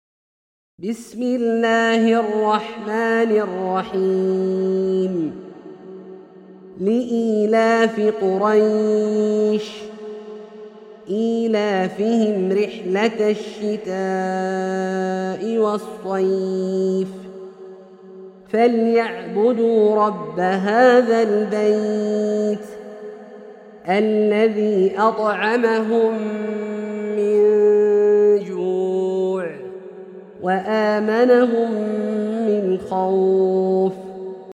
سورة قريش - برواية الدوري عن أبي عمرو البصري > مصحف برواية الدوري عن أبي عمرو البصري > المصحف - تلاوات عبدالله الجهني